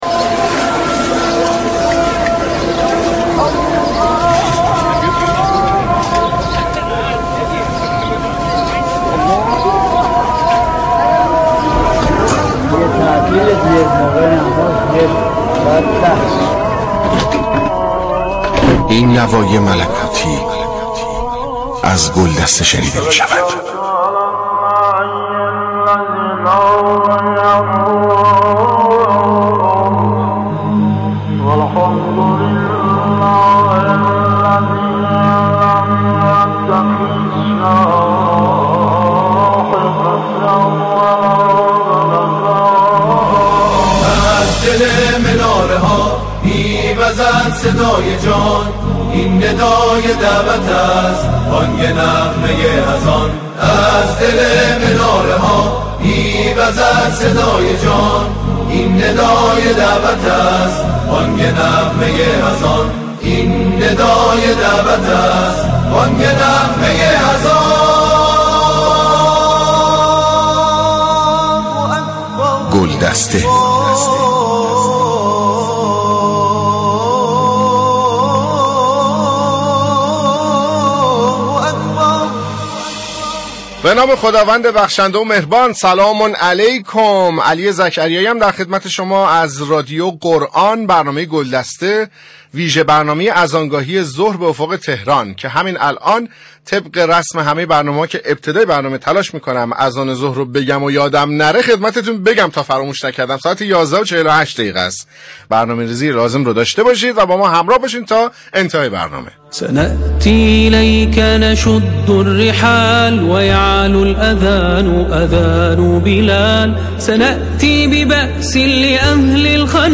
«گلدسته» عنوان ویژه‌برنامه رادیو قرآن است که لحظاتی پیش از اذان ظهر به وقت تهران پخش می‌شود.
دعاخوانی و اناشید، تدبر در معانی و مفاهیم یک آیه در روز، پخش فرازهایی از قاریان شهیر، گزارش میدانی از یکی از مساجد سراسر کشور، تبیین یک حکم شرعی و پخش فرازهایی از سخنرانی‌های حجت‌الاسلام والمسلمین محسن قرائتی و مرحوم آیت‌الله مجتهدی تهرانی با موضوع نماز از جمله آیتم‌های این برنامه است.